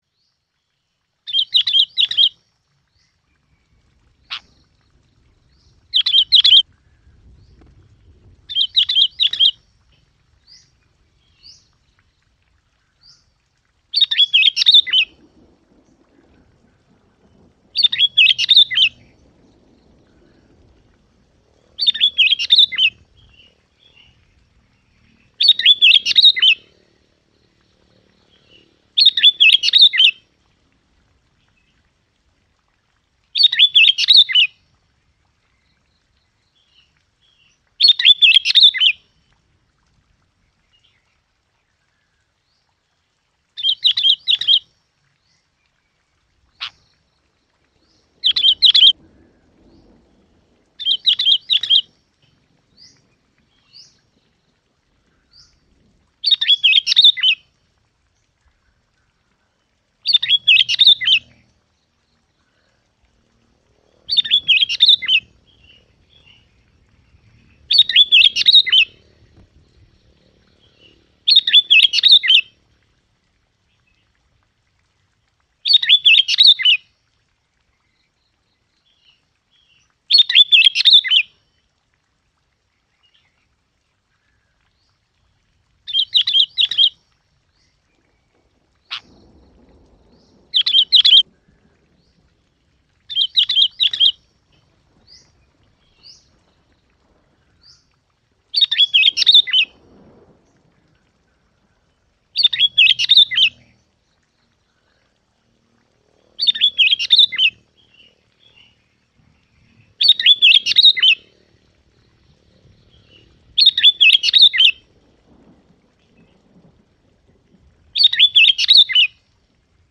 Tải tiếng Chào Mào đít vàng hót mp3 với chất lượng cao, không có tạp âm, âm thanh to và rõ ràng. Chim Chào Mào đít vàng là một trong những loài chim có giọng hót hay nhất.
Tiếng Chào Mào đít vàng
Âm thanh sống động, kết hợp với cảnh thiên nhiên núi rừng, hoang dã, mang lại trải nghiệm thú vị cho người nghe.
tieng-chao-mao-dit-vang-www_tiengdong_com.mp3